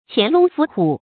潛龍伏虎 注音： ㄑㄧㄢˊ ㄌㄨㄙˊ ㄈㄨˊ ㄏㄨˇ 讀音讀法： 意思解釋： 潛藏的蛟龍，潛伏的猛虎。比喻人才尚未被擢用。